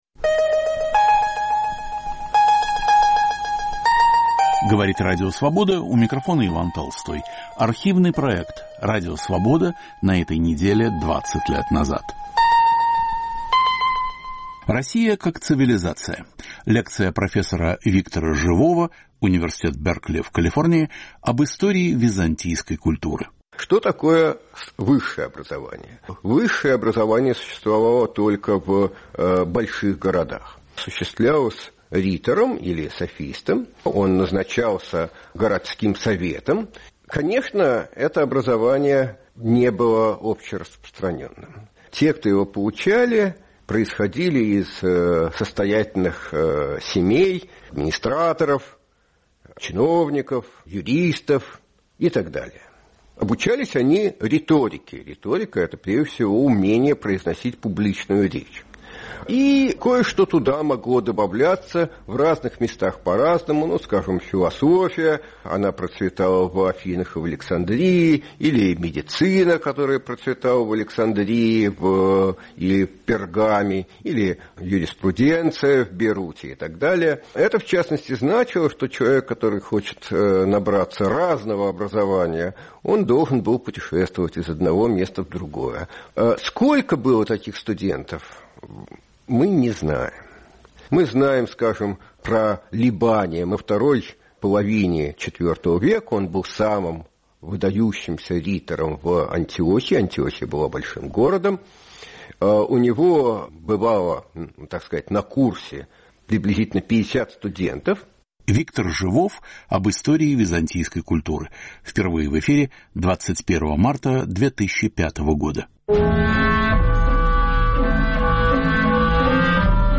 "Новая эра", лекция третья.
Московский неофициальный вечер 1981 года.